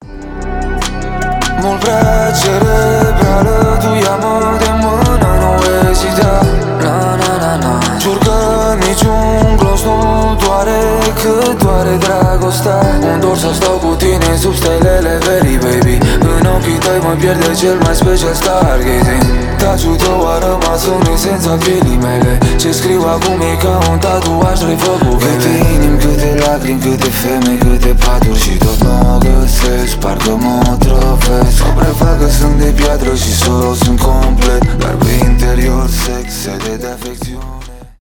рэп
pop rap